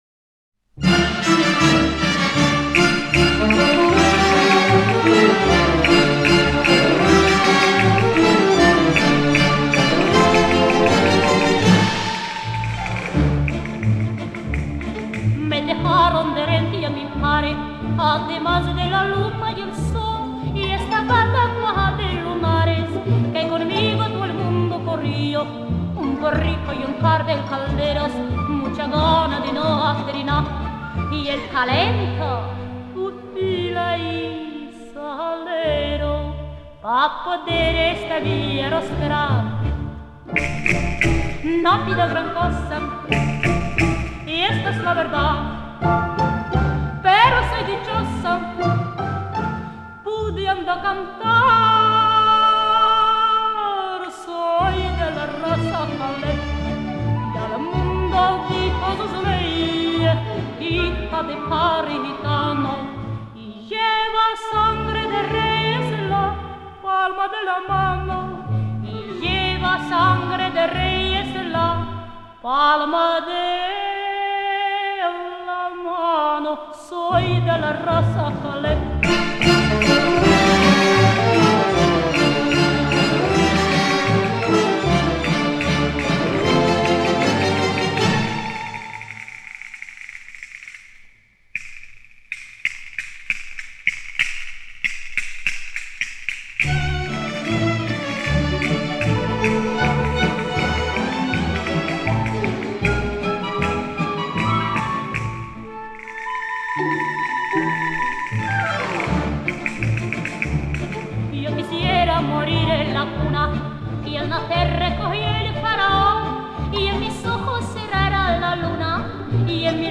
32 Наследство цыган, испанская народная песня (на испанском языке).mp3